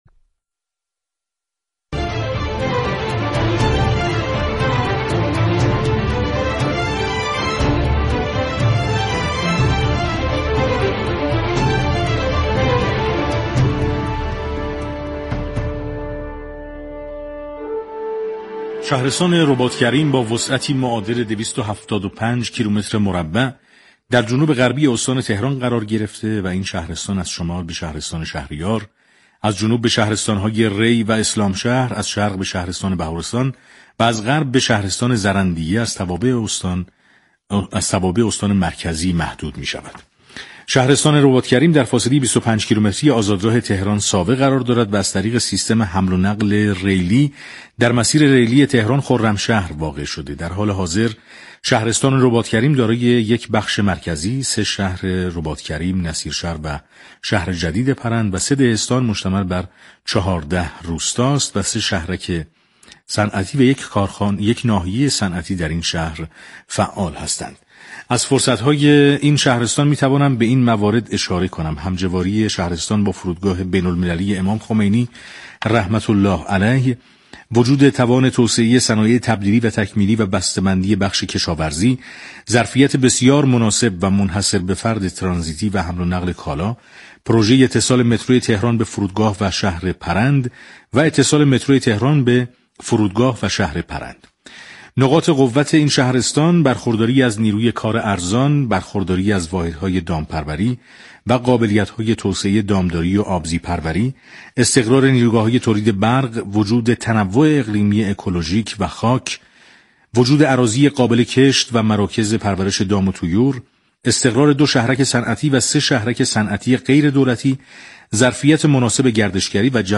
عبدالله نوحی فرماندار شهرستان بهارستان در گفت و گوی تلفنی با برنامه پل مدیریت 24 بهمن